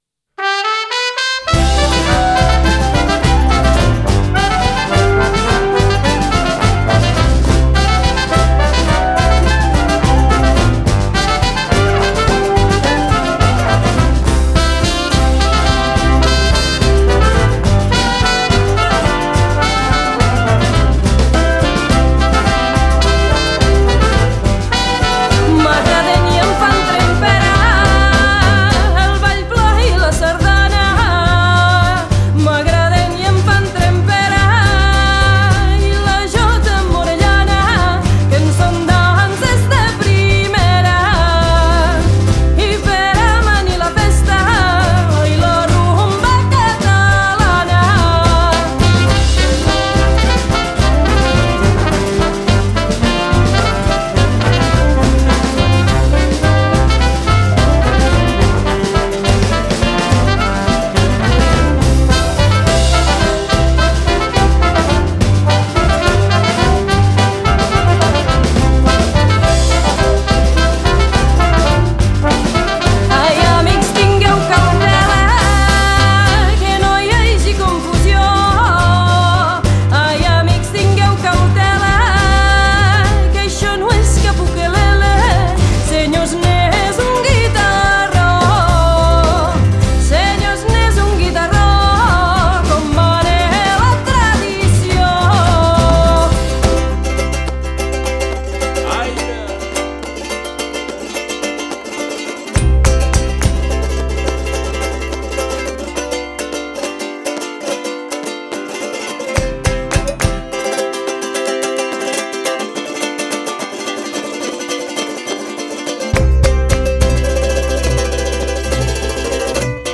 • Jotada (jota improvisada). La gran jotada